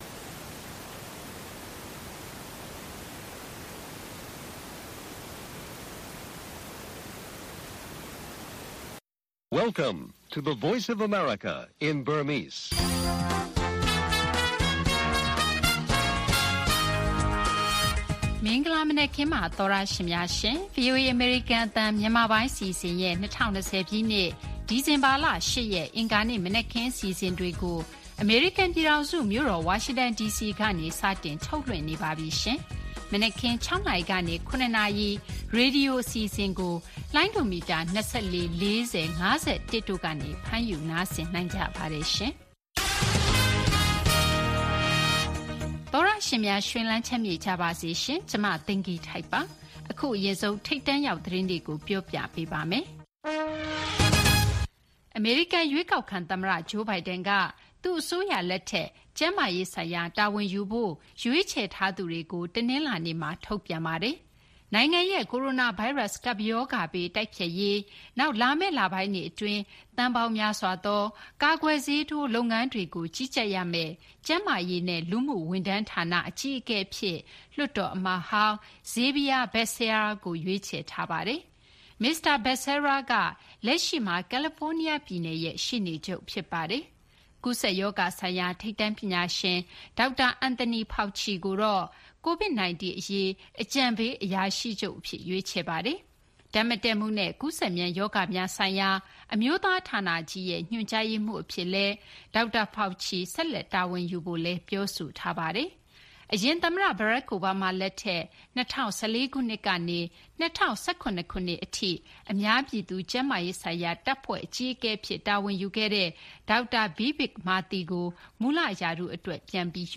အင်္ဂါနေ့ နံနက်ခင်း ရေဒီယိုအစီအစဉ် တိုက်ရိုက်ထုတ်လွှင့်ချက် (Zawgyi/Unicode) ဗြိတိန် နိုင်ငံမှာ ဒီကနေ့ကစပြီး နိုင်ငံတဝန်း ကိုဗစ်-၁၉ ကာကွယ်ဆေး စတင်ထိုးပေးတော့မယ် သတင်းနဲ့အတူ နောက်ဆုံးရ မြန်မာနဲ့ နိုင်ငံတကာသတင်းတွေ တိုက်ပွဲအရှိန်လျော့သွားတဲ့ ရခိုင်မှာ ဒေသခံတွေ နေရပ်မပြန်ရဲတာဘာကြောင့်လဲ စတဲ့ သတင်းပေးပို့ချက်တွေနဲ့အတူ သတင်းထောက်မှတ်စု၊ စီးပွားရေးကဏ္ဍ၊ ဘလော့ဂါတွေပြောသမျှ သက်တံ့ရောင်သတင်းလွှာ စတဲ့ အစီအစဉ်တွေကို နားဆင်ရမှာပါ။ ................